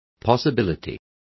Complete with pronunciation of the translation of possibility.